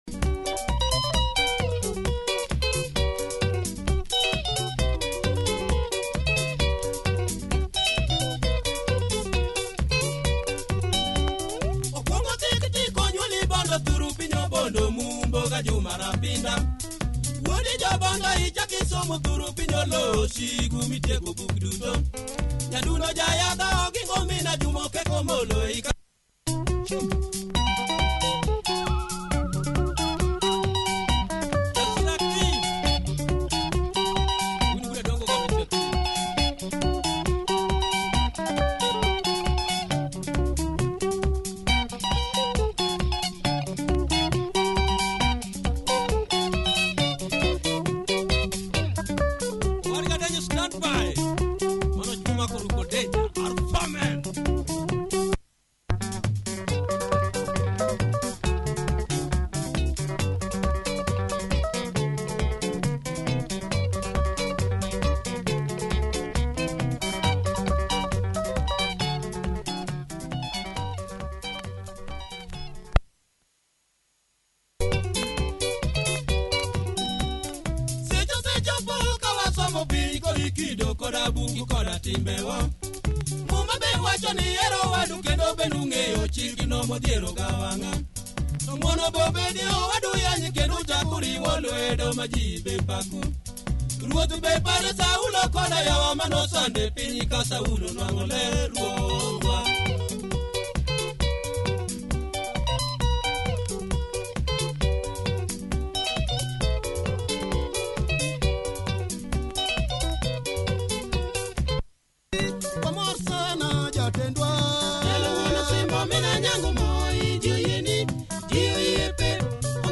Pumping luo benga, check audio of both sides! https